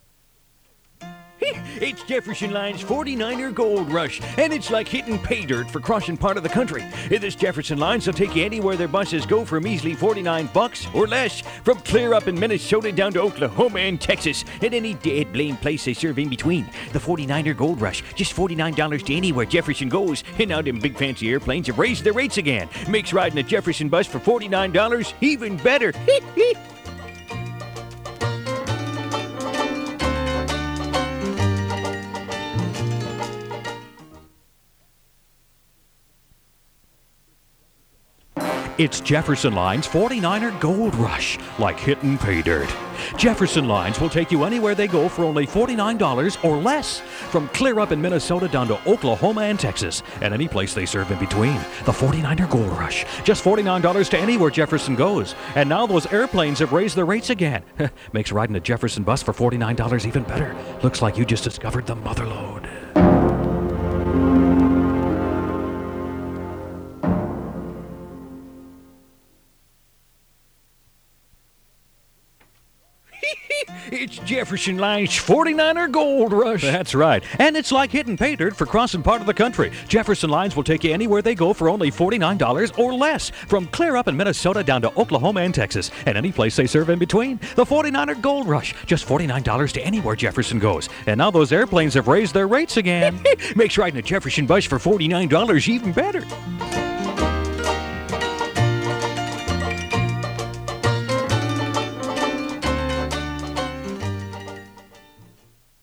Jefferson Lines radio spot, 30 seconds, undated. 1 master audio file (1 minute, 45 seconds): WAV (8.9 MB) and 1 user audio file: MP3 (1.8 MB).